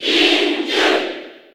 File:Link Cheer JP SSB4.ogg
Link_Cheer_JP_SSB4.ogg.mp3